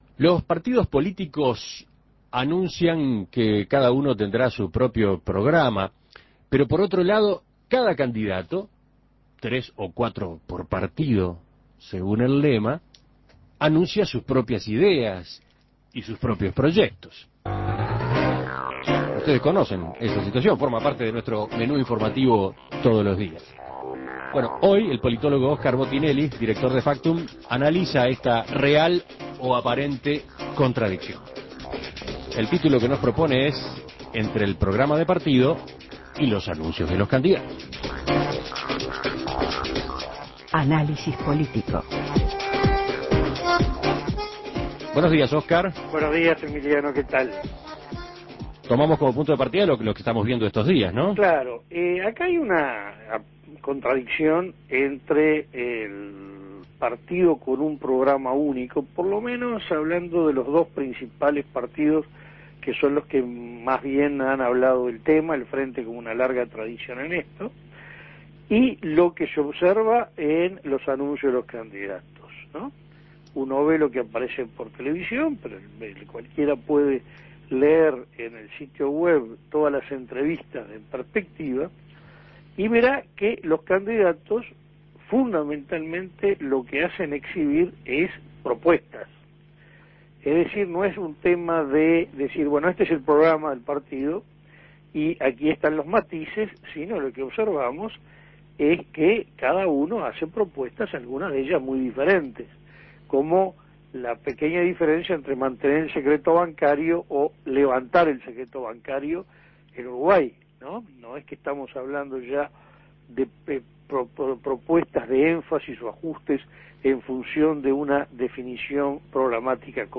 Análisis Político